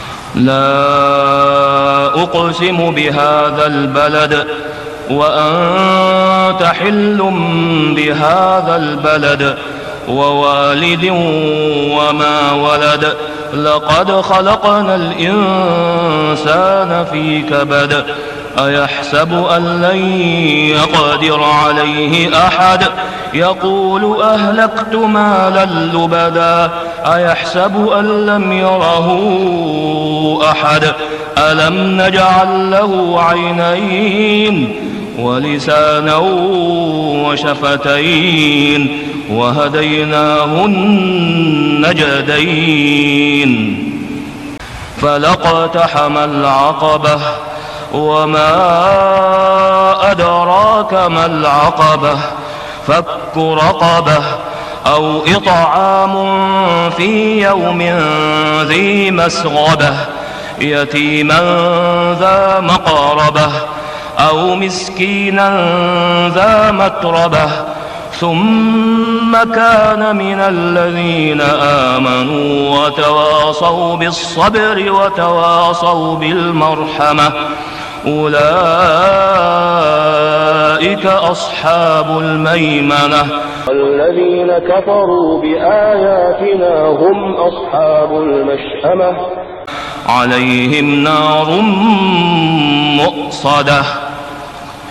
سورة البلد > السور المكتملة للشيخ أسامة خياط من الحرم المكي 🕋 > السور المكتملة 🕋 > المزيد - تلاوات الحرمين